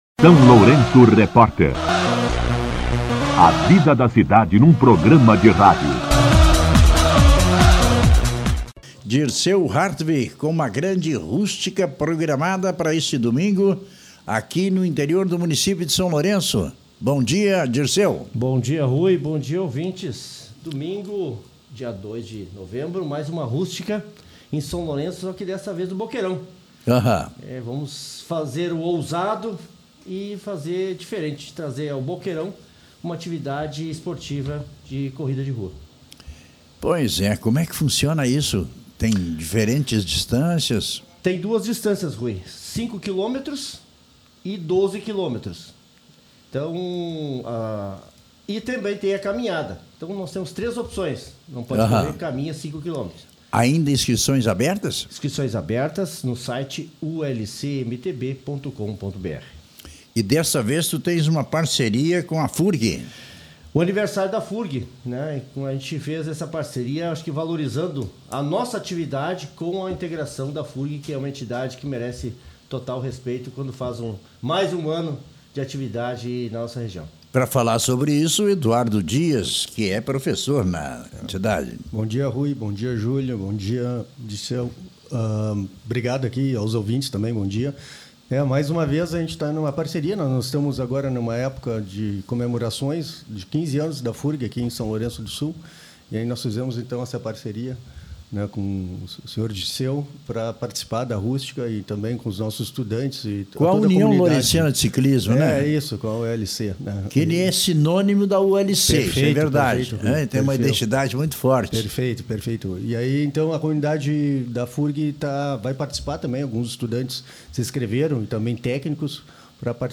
em entrevista ao SLR RÁDIO desta quarta-feira